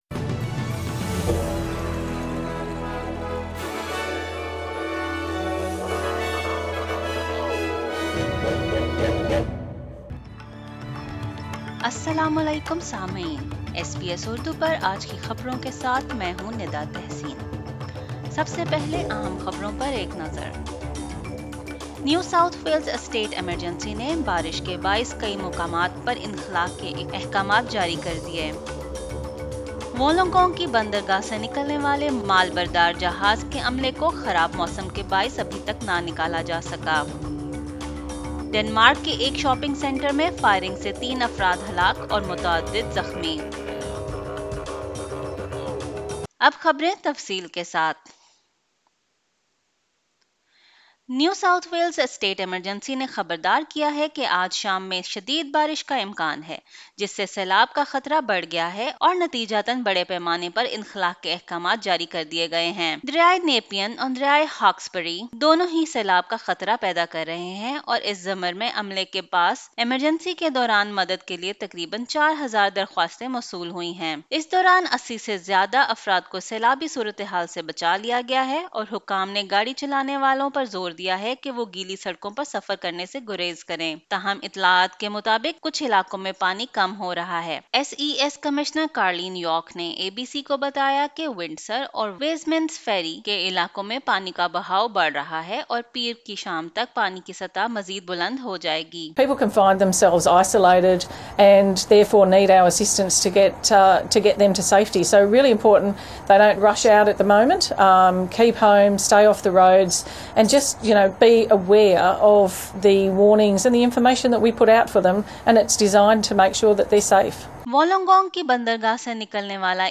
SBS Urdu News 04 July 2022